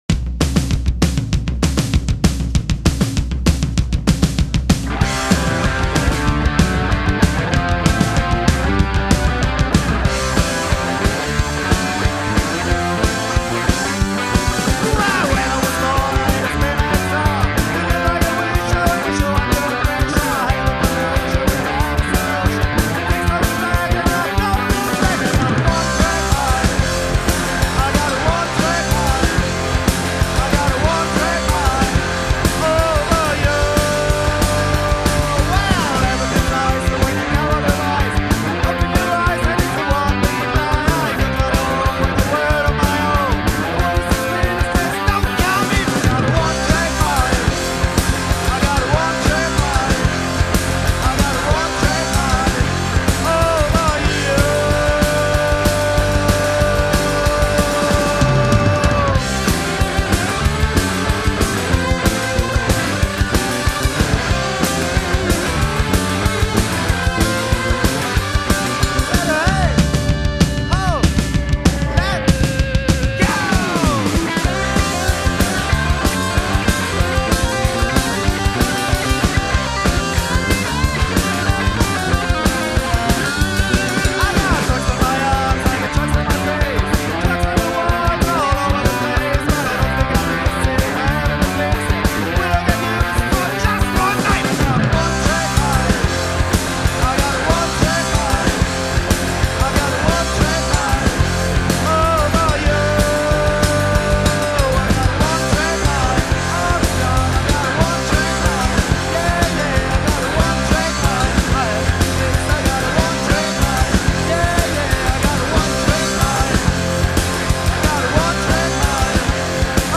Rock & Roll, Punk Rock